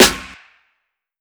Groundhogs Day Snare.wav